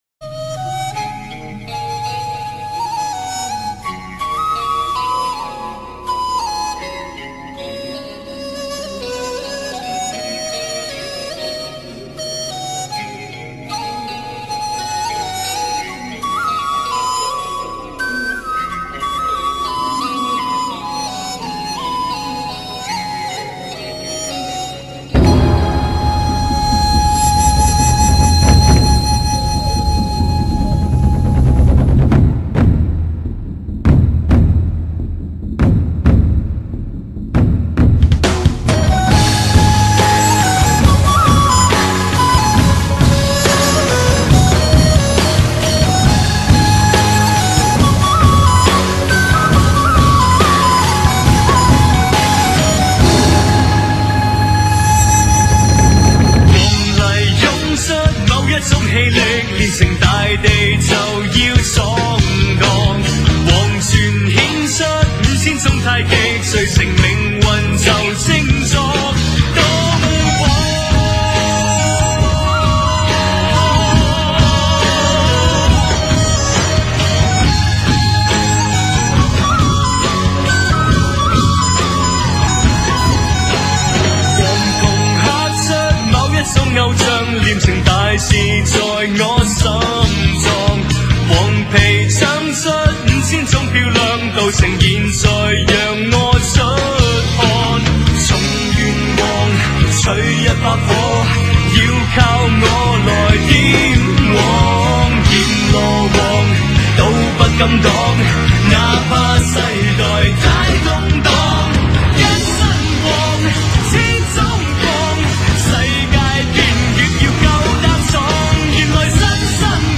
节奏很强憾的音乐